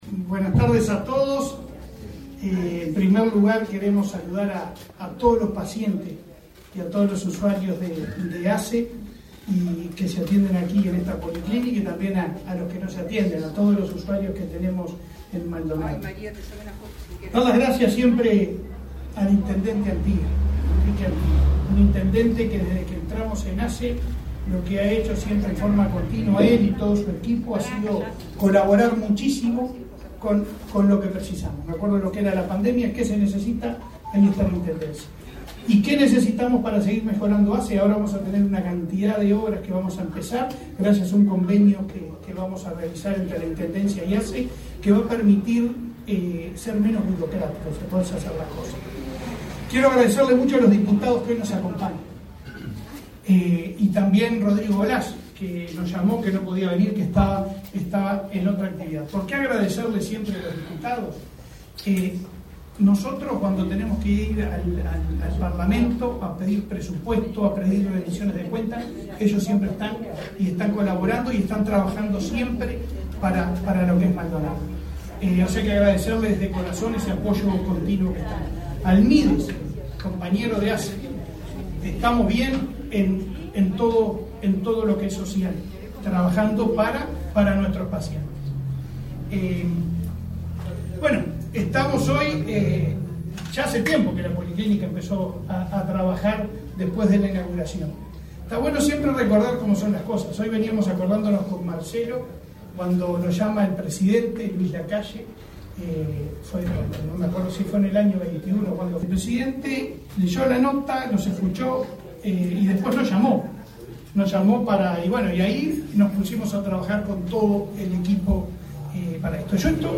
Palabras del presidente de ASSE, Leonardo Cirpiani
Palabras del presidente de ASSE, Leonardo Cirpiani 01/02/2024 Compartir Facebook X Copiar enlace WhatsApp LinkedIn La Administración de Servicios de Salud del Estado (ASSE) inauguró, este 1.° de febrero, una policlínica y entregó ambulancia en el departamento de Maldonado. El presidente de ASSE, Leonardo Cipriani, disertó en el evento.